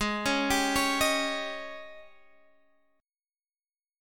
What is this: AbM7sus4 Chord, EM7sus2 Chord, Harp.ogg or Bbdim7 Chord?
AbM7sus4 Chord